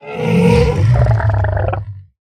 Minecraft Version Minecraft Version snapshot Latest Release | Latest Snapshot snapshot / assets / minecraft / sounds / mob / hoglin / converted1.ogg Compare With Compare With Latest Release | Latest Snapshot